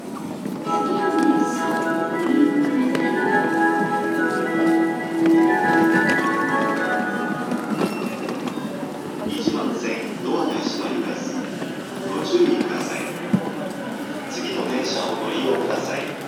大井町駅　Oimachi Station ◆スピーカー：JVC横長型
発車メロディは2ターンで1コーラスです。
1番線発車メロディー